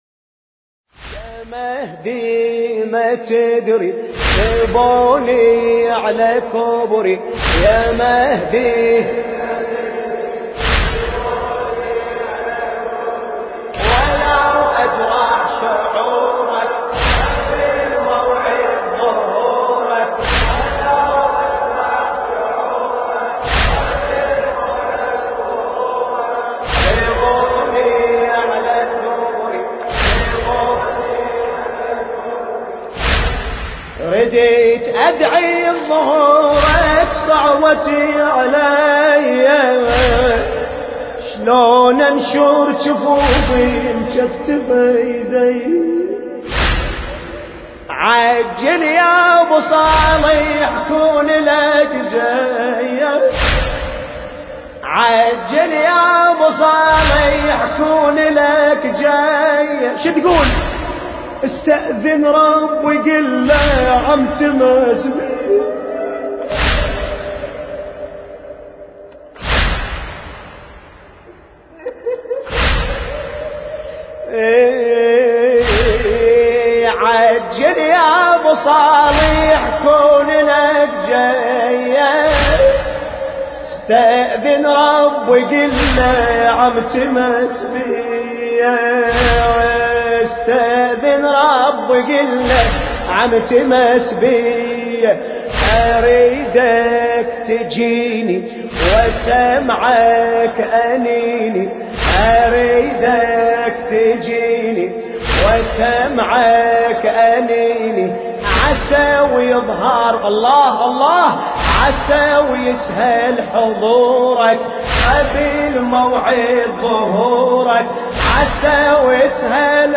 المكان: العتبة العسكرية المقدسة
ذكرى أربعينية الإمام الحسين (عليه السلام)